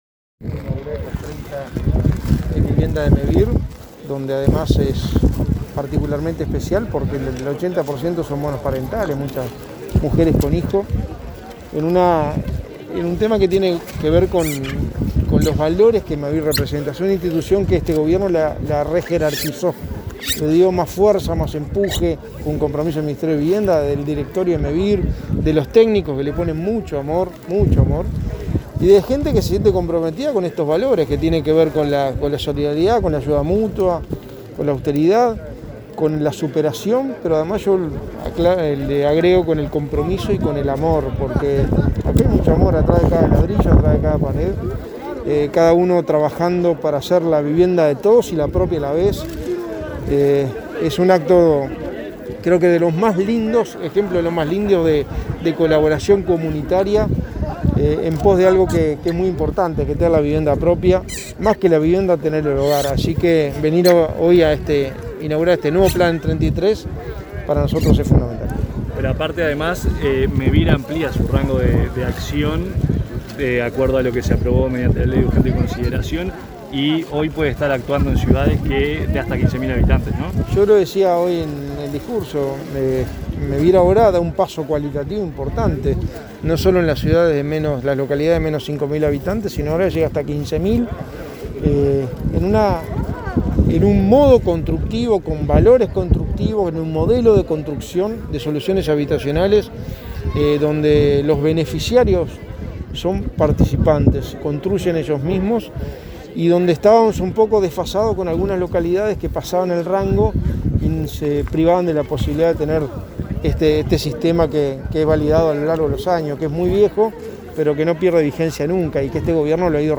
Declaraciones a la prensa del secretario de Presidencia, Álvaro Delgado
El secretario de Presidencia, Álvaro Delgado, participó de la inauguración de un complejo de 30 viviendas de Mevir en Santa Clara de Olimar,